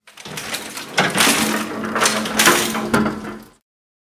gurneyunload.ogg